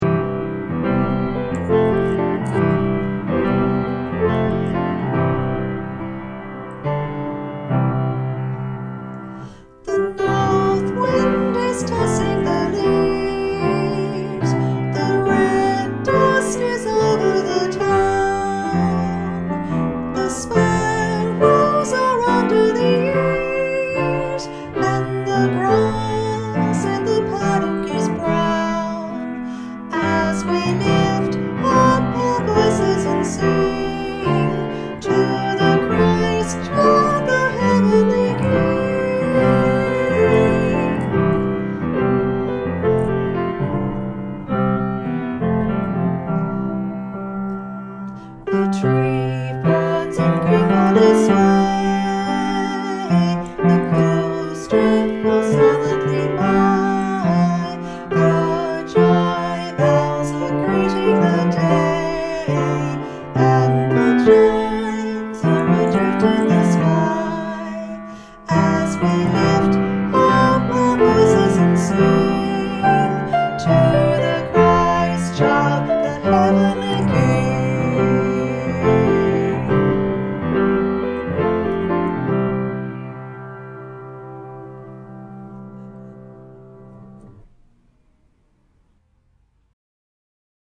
Vox Populi Choir is a community choir based in Carlton and open to all comers.
Christmas_Day-bass.mp3